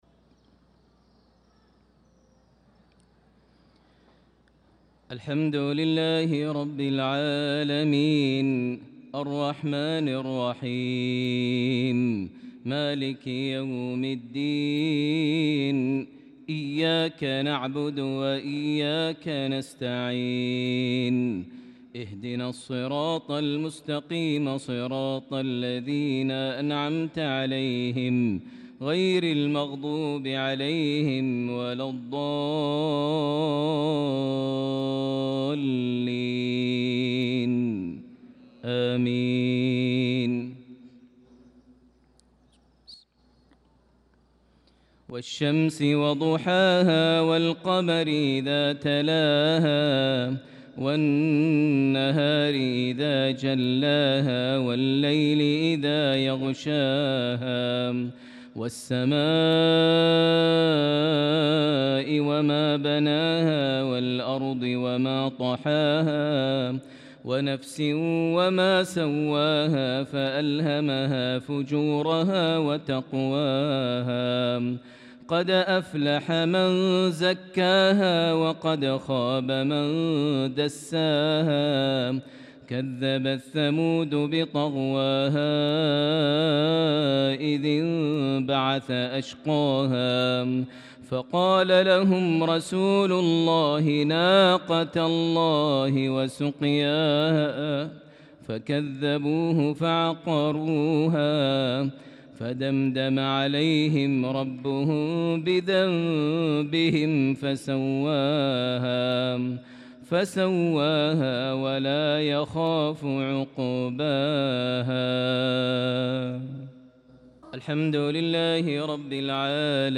صلاة المغرب للقارئ ماهر المعيقلي 8 شوال 1445 هـ
تِلَاوَات الْحَرَمَيْن .